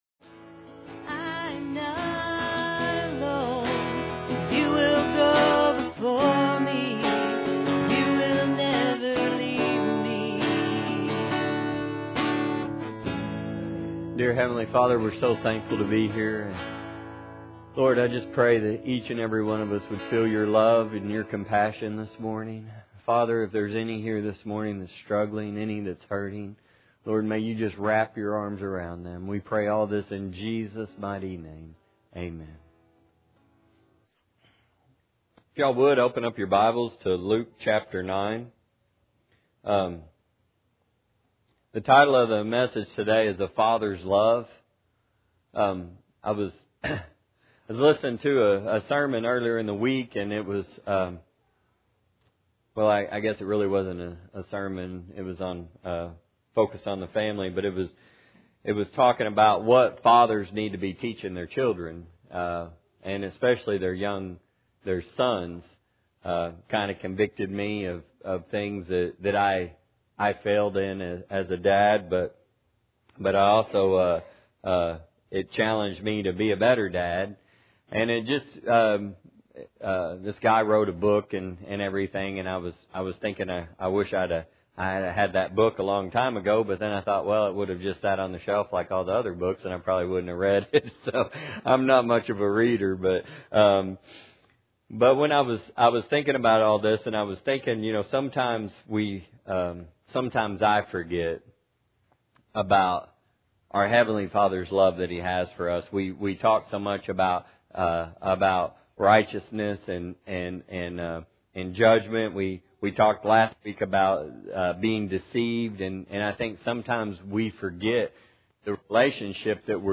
Passage: Luke 9:51-55; 2 Peter 3:9; John 13:23; 1 John 4:7-21; 1 John 3:1-6; Luke 11:11-13; 2 Corinthians 6:18; Psalm 103:13 Service Type: Sunday Morning